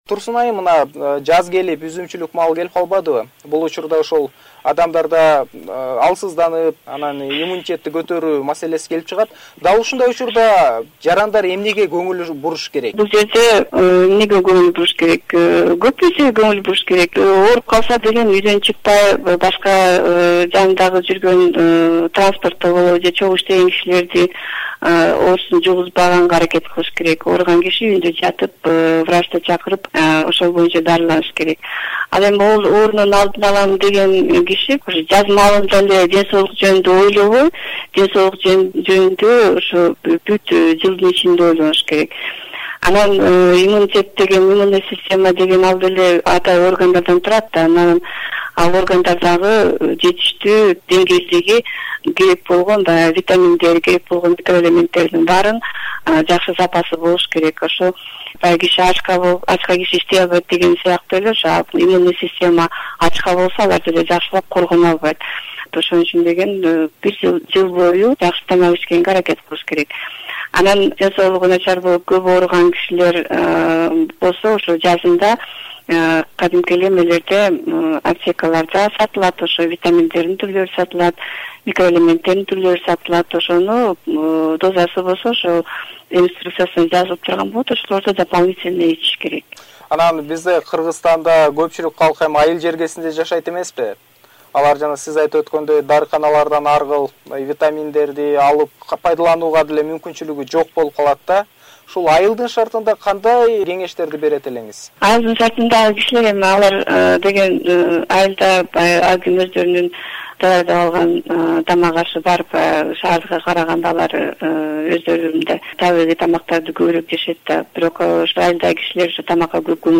Special Radio Package: Interview